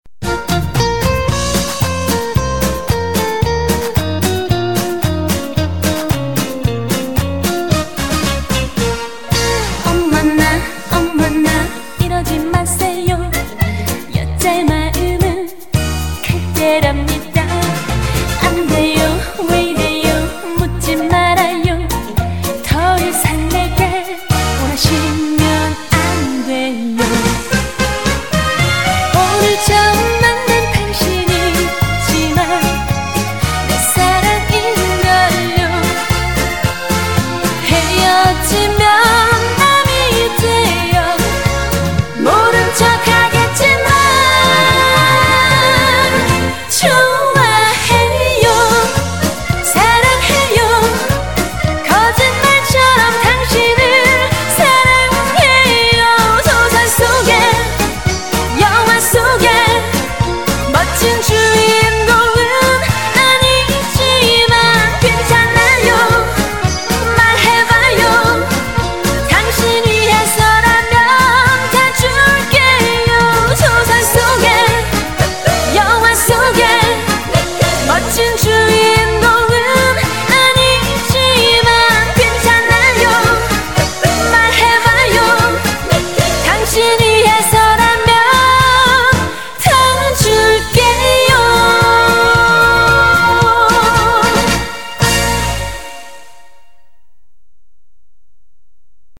BPM112--1
Audio QualityPerfect (High Quality)